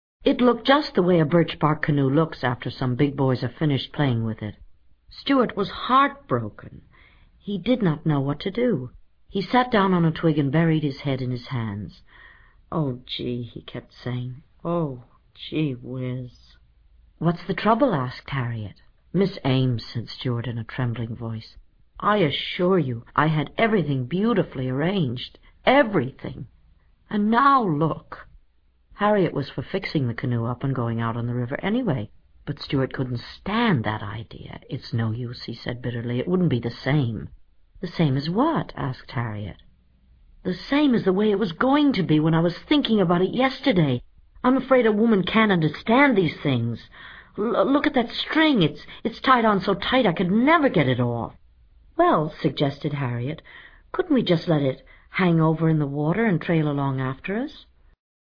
在线英语听力室精灵鼠小弟 第83期:该死的破坏狂的听力文件下载, 《精灵鼠小弟》是双语有声读物下面的子栏目，是学习英语，提高英语成绩的极好素材。